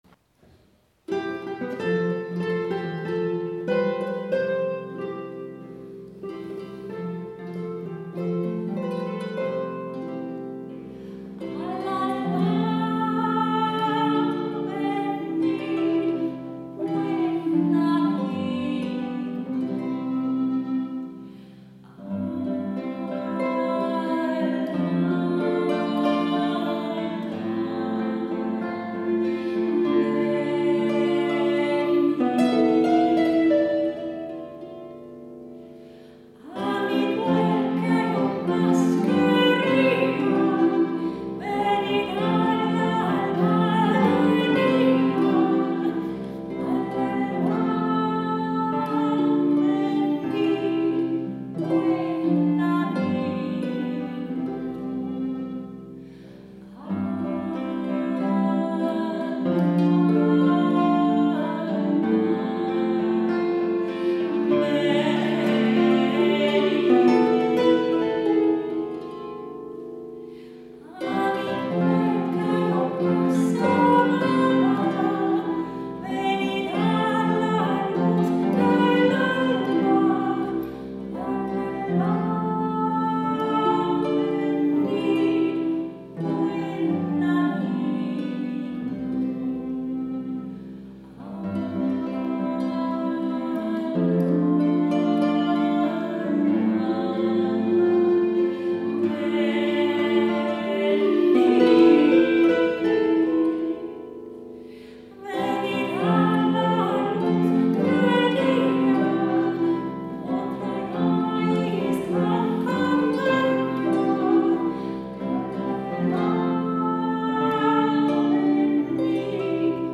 Die Stücke spiegeln höfische Liedkultur des 16. und 17. Jahrhunderts: